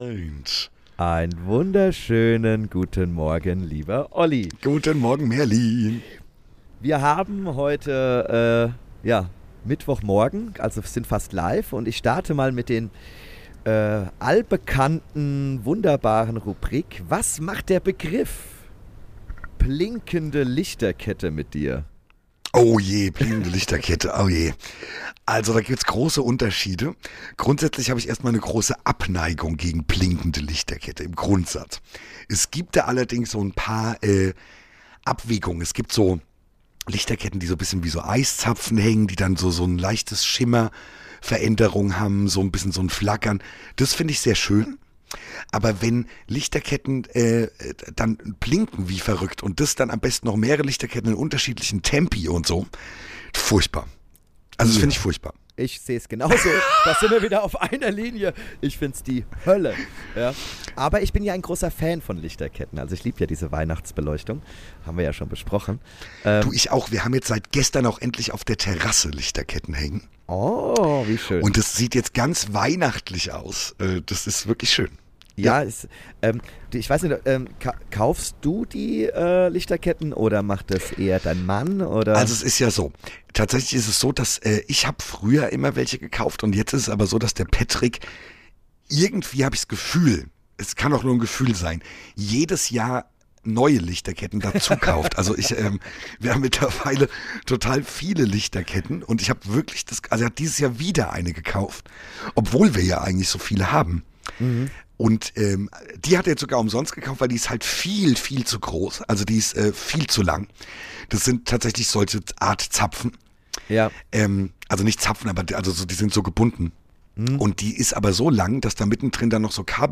Wie immer ungeschnitten und roh haben wir eine einstündige Folge für euch bzw. für uns aufgenommen.